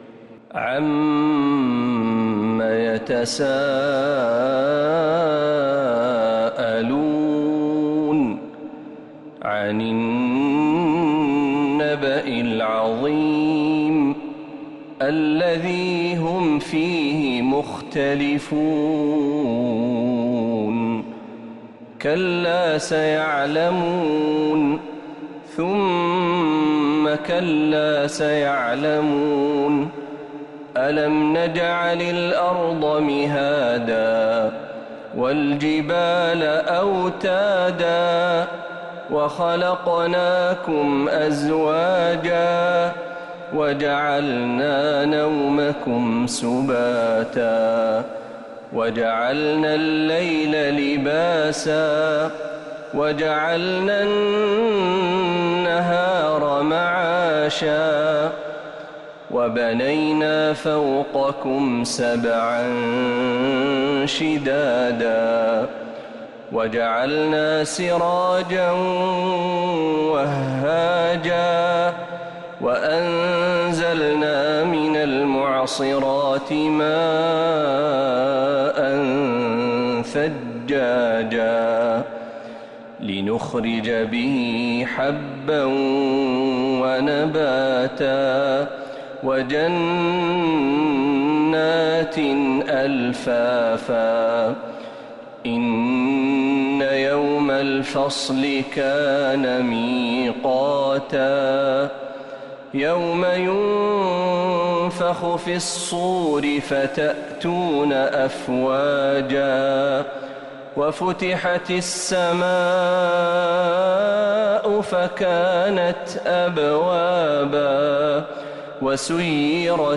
سورة النبأ كاملة من الحرم النبوي